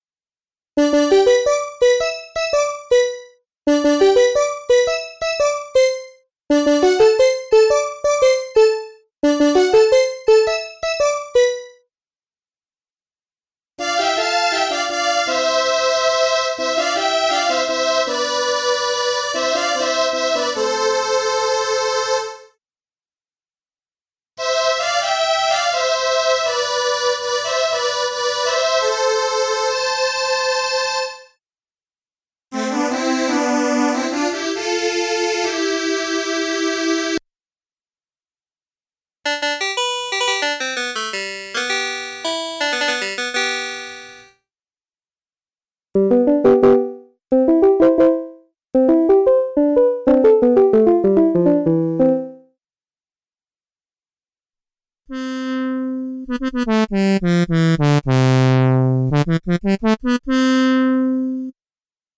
Traemos para tu Korg Kronos el sonido inconfundible del Crumar Orchestrator (Multiman), la joya analógica italiana que definió el estilo de las grandes agrupaciones de México y Latinoamérica.
1. Piano: El sonido percusivo y brillante clásico.
2. Clavichord: Textura funky y nasal.
3. Brass (Metales): Cálidos, con ese filtro analógico inigualable.
4. Violin: Cuerdas suaves y envolventes.
5. Cello: Graves profundos y ricos.
• Sampleado notas del teclado original.